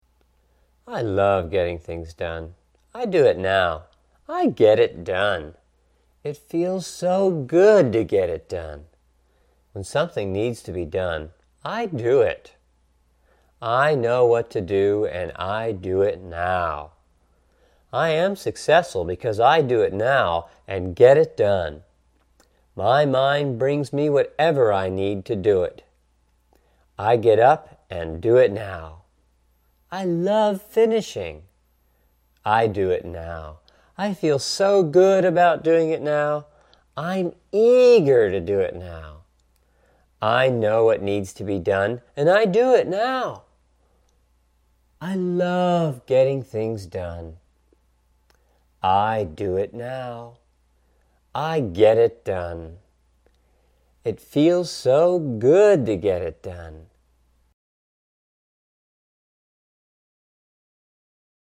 These are the same suggestions on the Stop Procrastination subliminal - only they are audible. Encouraging suggestions such as "I do it Now!" and I love Getting Thins Done!"